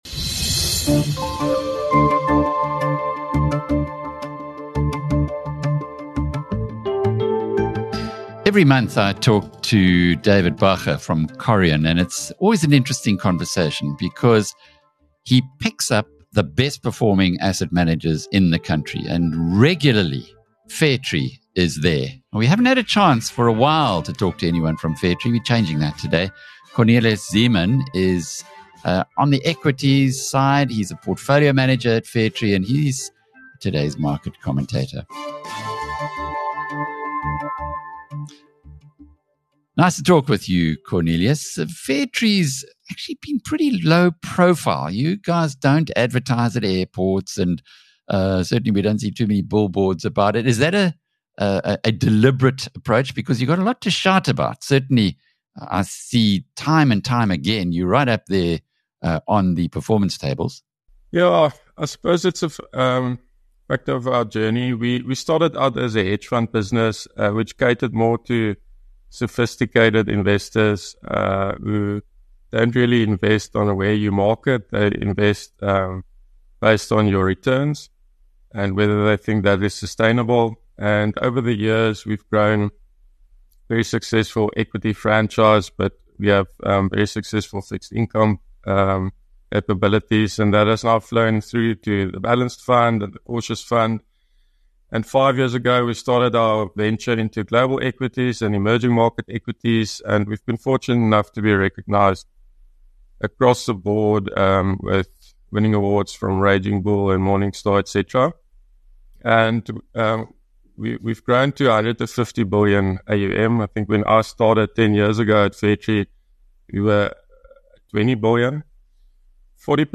In this insightful interview